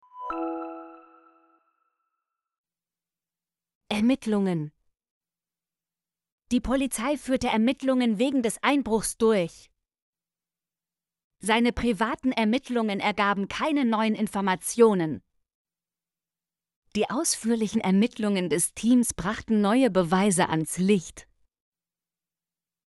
ermittlungen - Example Sentences & Pronunciation, German Frequency List